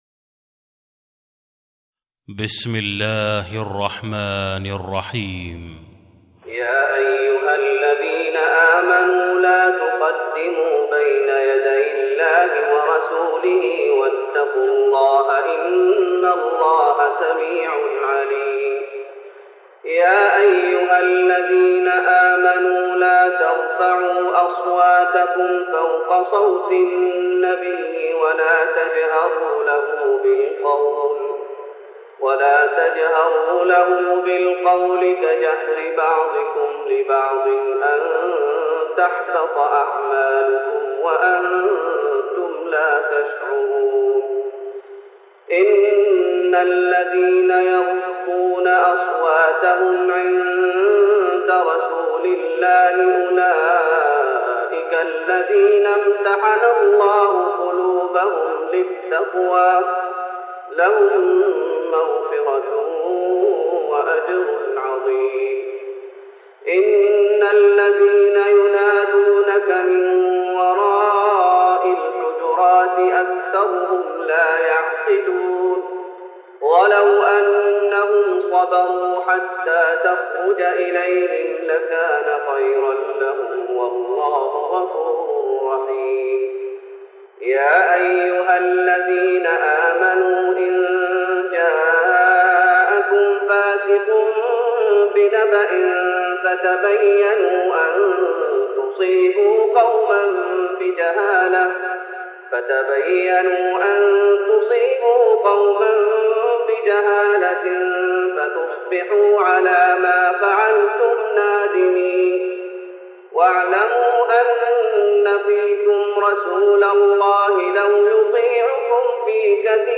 تراويح رمضان 1414هـ من سورة الحجرات Taraweeh Ramadan 1414H from Surah Al-Hujuraat > تراويح الشيخ محمد أيوب بالنبوي 1414 🕌 > التراويح - تلاوات الحرمين